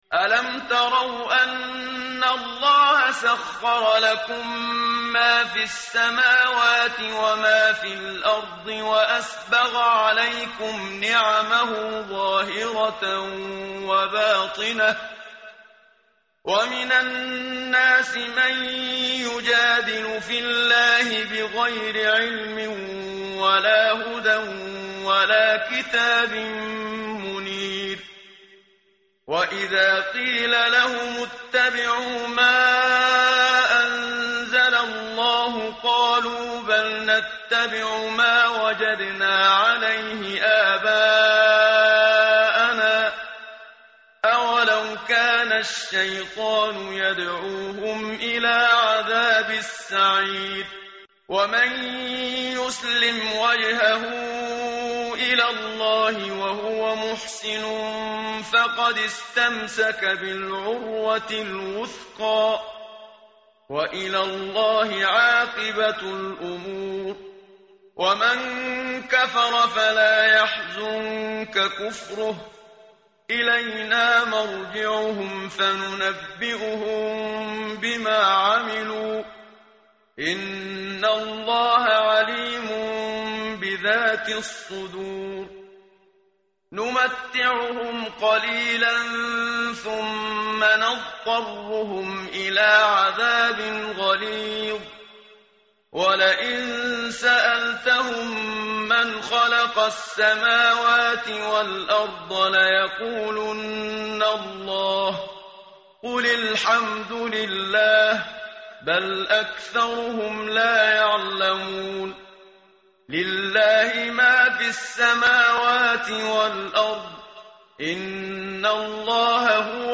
متن قرآن همراه باتلاوت قرآن و ترجمه
tartil_menshavi_page_413.mp3